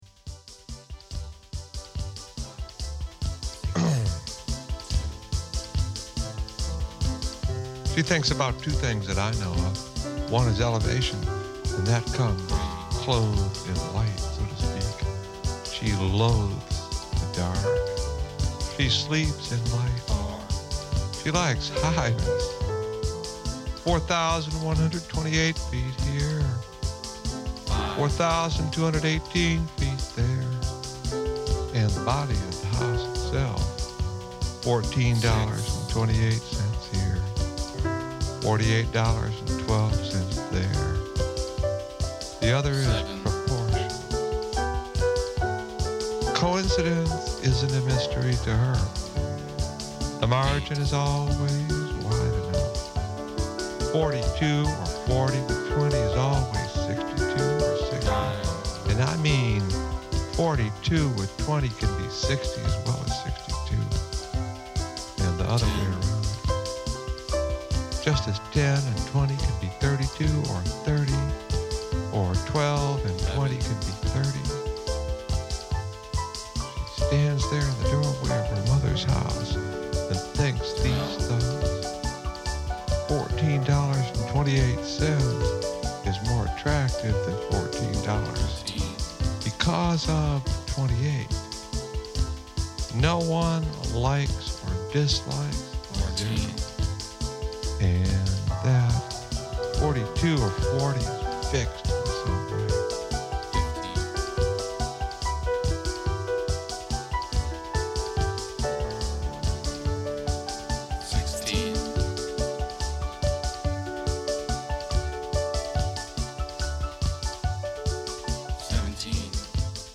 Recommned listening : speech & music. multivoice